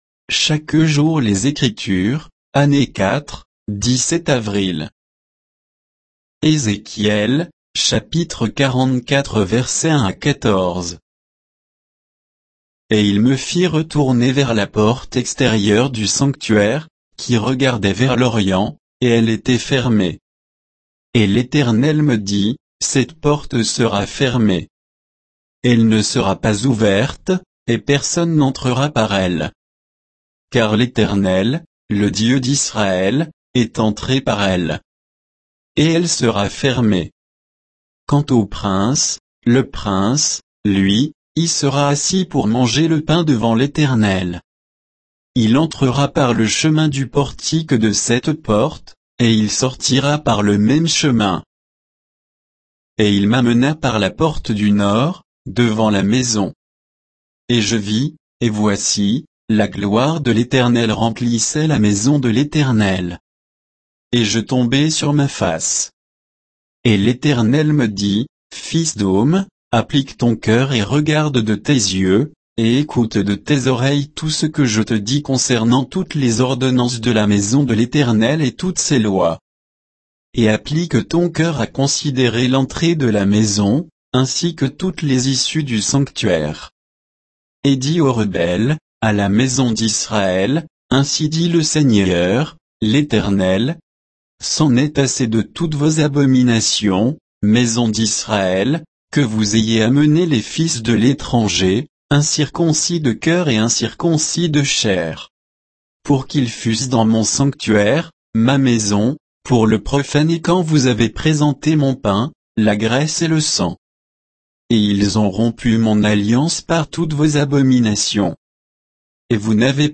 Méditation quoditienne de Chaque jour les Écritures sur Ézéchiel 44